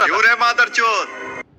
kyu re madarchod cid Meme Sound Effect